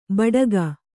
♪ baḍaga